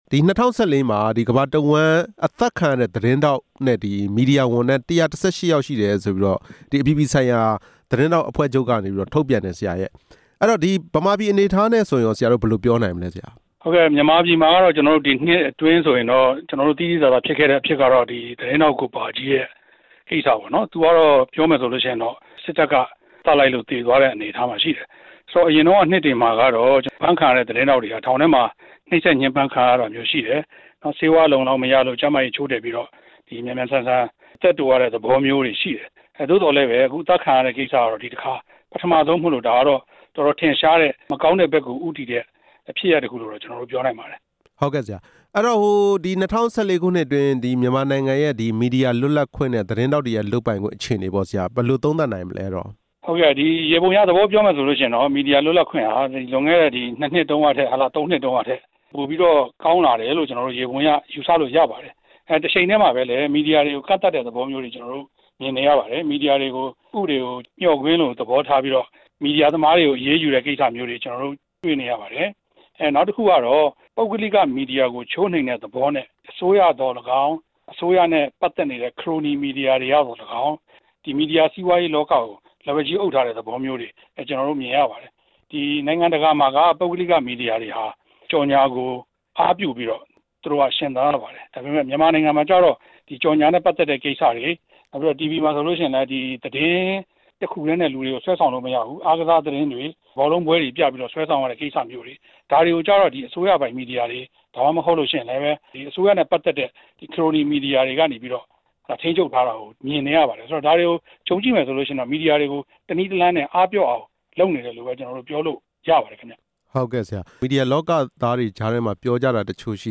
၂ဝ၁၅ ခုနှစ်ဟာ မီဒီယာရဲ့ အခန်းကဏ္ဍ အရေးကြီးတဲ့ အကြောင်း မေးမြန်းချက်